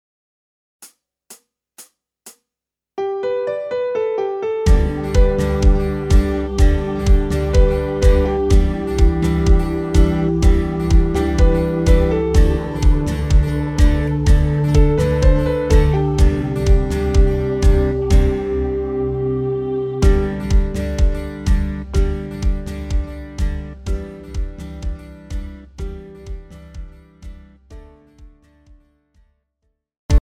Žánr: Pop
BPM: 125
Key: G